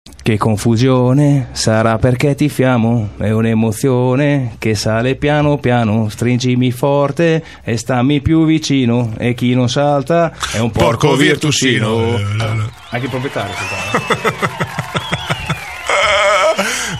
cantare il coro